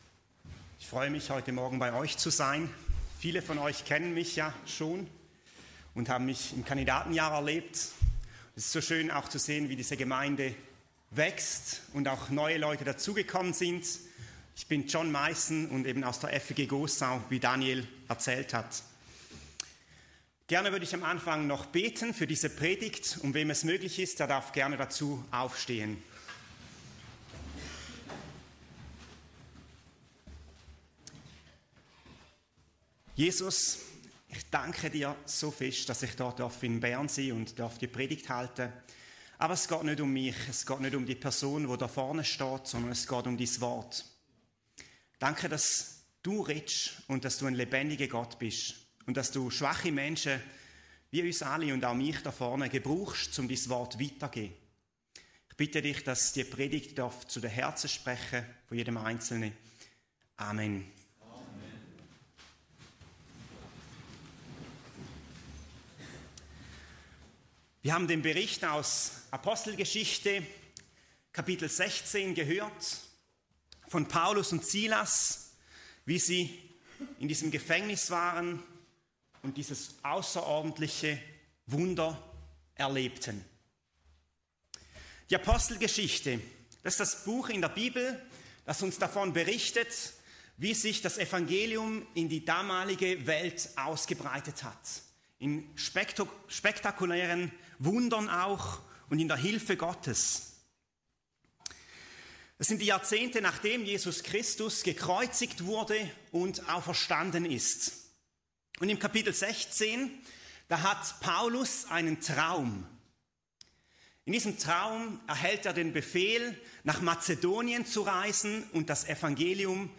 In dieser Predigt betrachten wir die Geschichte von Paulus und Silas im Gefängnis aus Apostelgeschichte 16 – ein kraftvolles Zeugnis davon, wie Gebet und Lob Gottes mitten in der grössten Not Hoffnung, Freiheit...